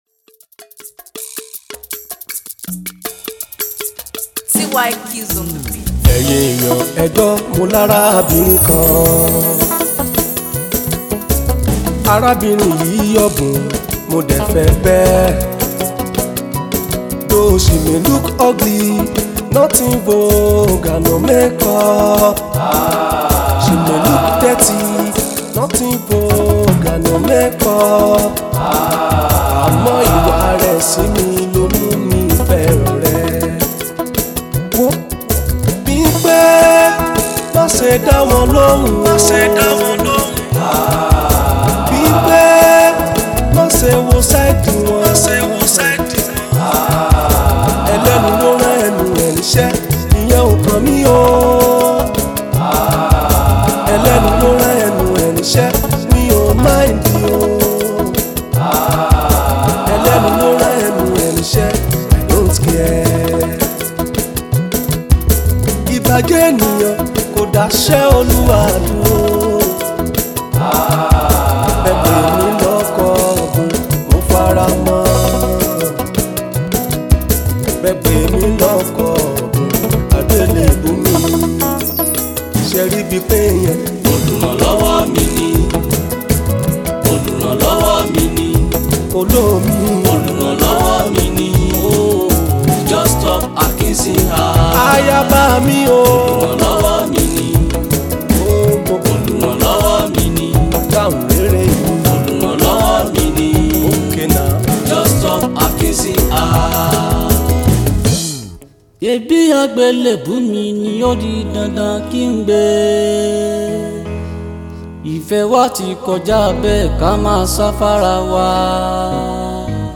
with a touch of total African rhymes
He’ s a musician, (An urban folk, Afro singer)
And He rename it to” urban folk & Afro.